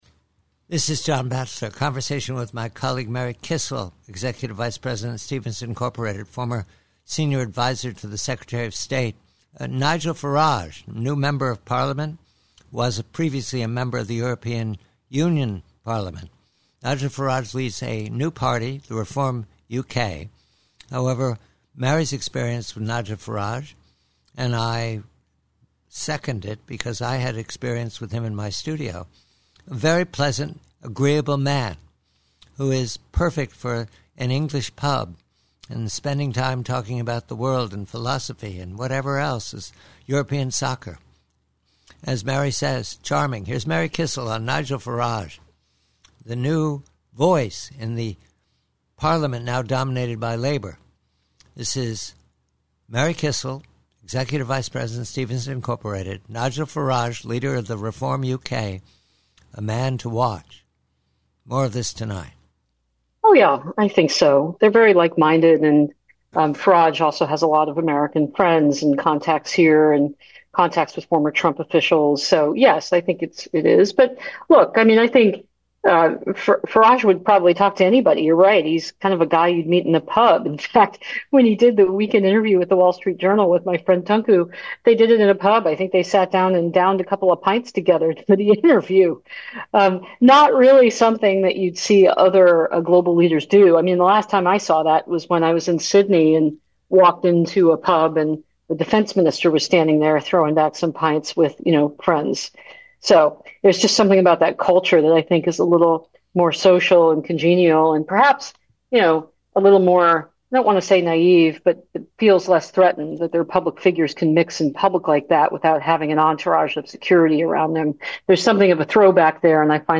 PREVIEW: REFORM UK: NIGEL FARAGE: Conversation